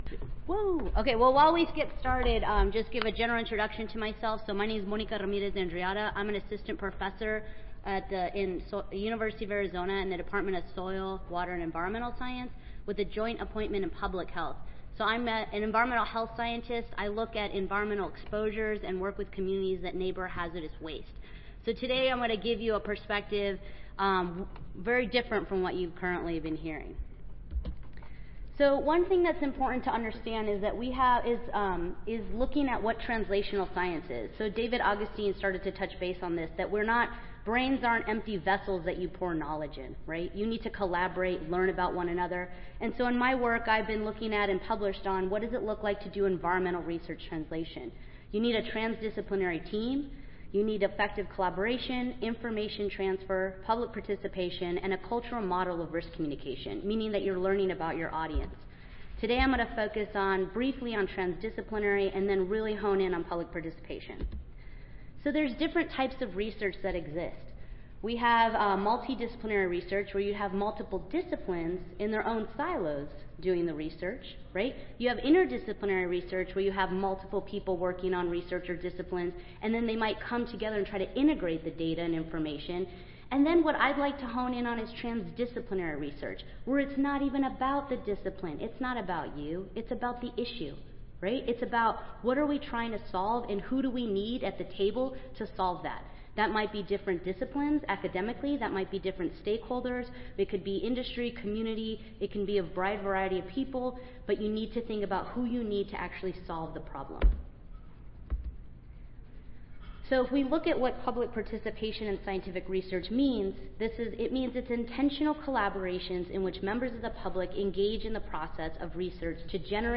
University of Arizona Audio File Recorded Presentation